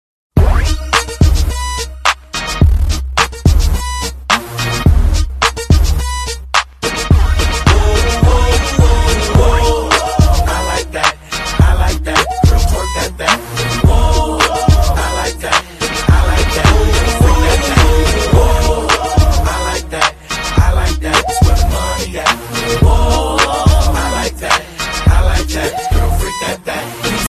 • Category Hip Hop